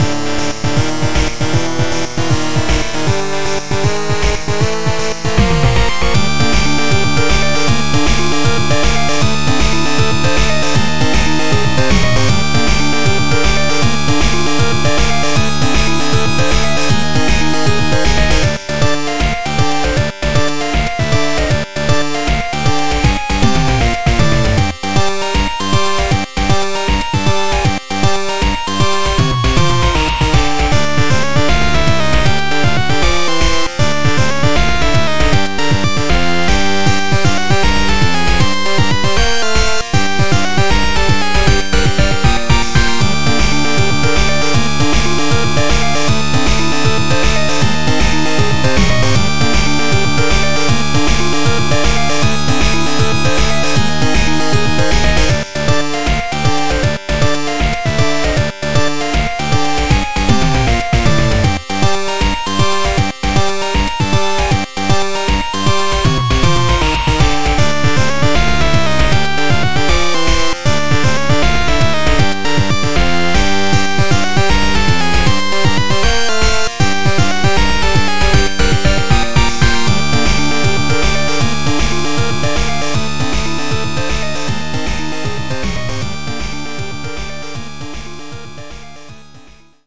156bpm